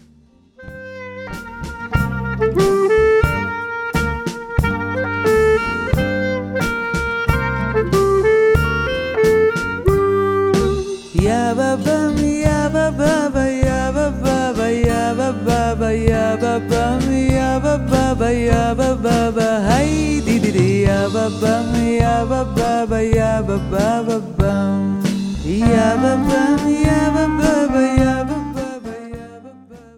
In her warm, deep voice
Folk